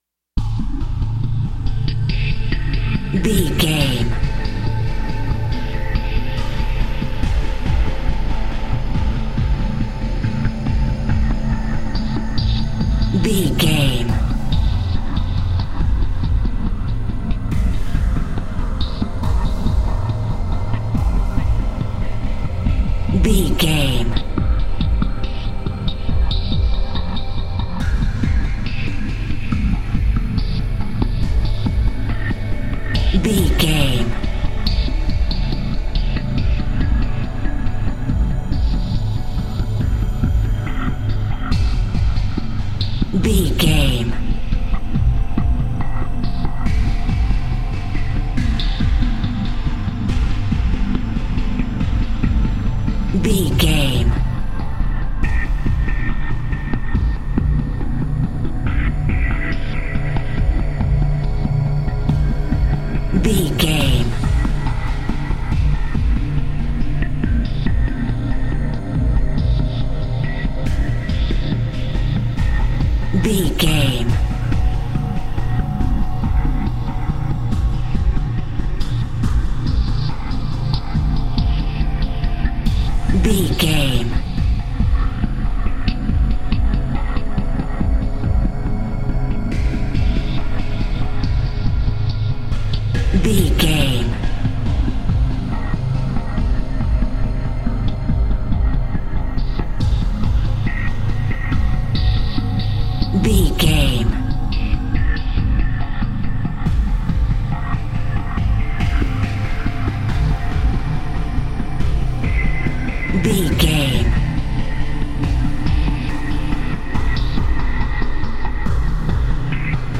Ionian/Major
synthesiser
drum machine
spooky